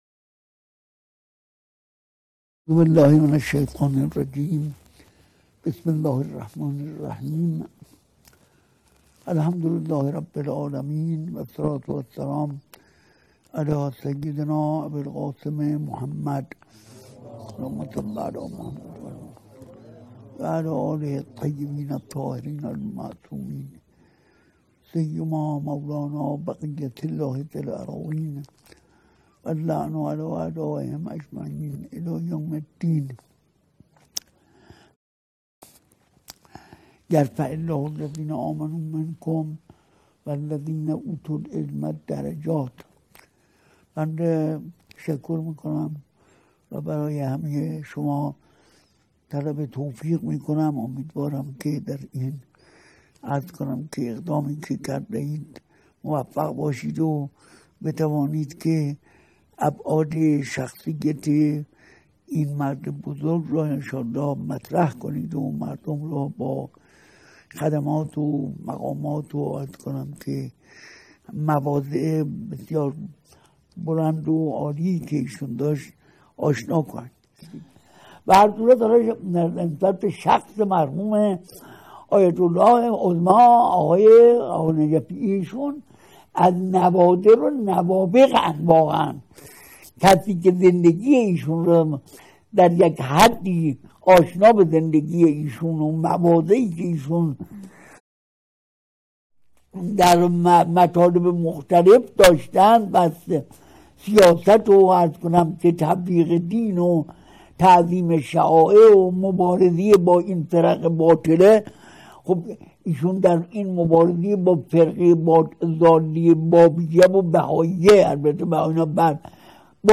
بیانات حضرت آیت الله صافی گلپایگانی دردیداربااعضای همایش بزرگداشت مرحوم آیت الله العظمی آقانجفی(ره) - خانه بیداری اسلامی